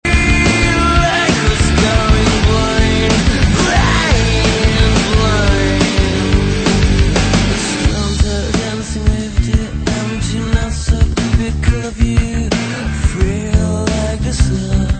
power-pop